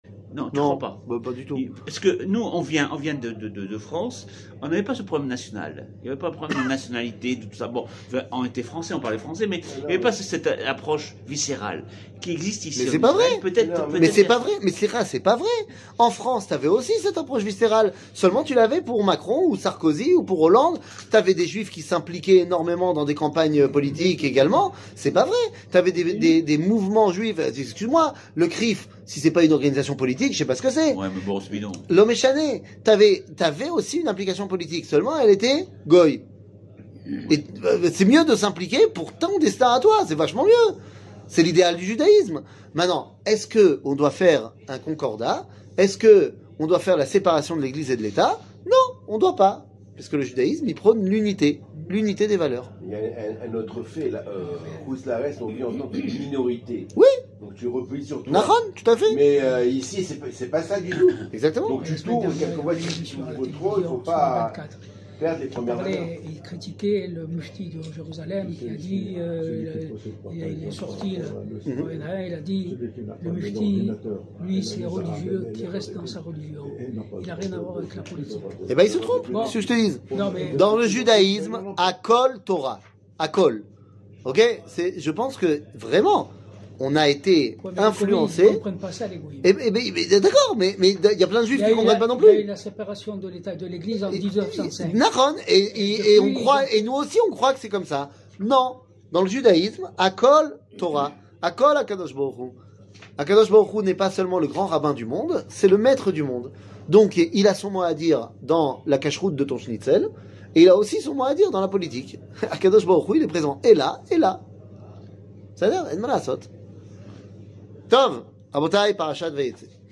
שיעור